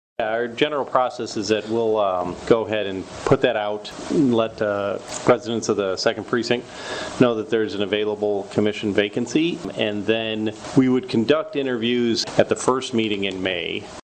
Sturgis City Clerk Ken Rhodes explains how the City will go about finding someone to fill Moyer’s position.